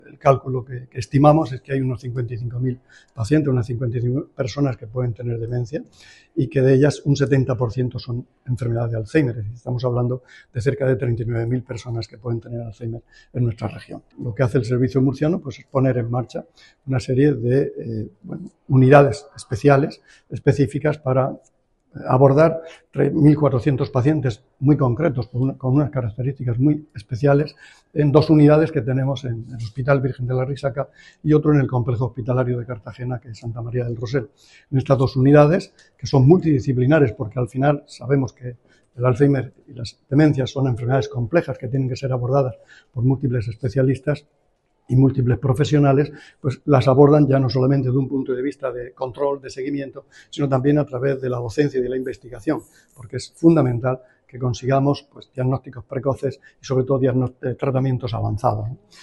Declaraciones del consejero de Salud sobre la incidencia y el tratamiento del Azheimer y las demencias en la Región de Murcia